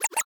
menu-direct-click.ogg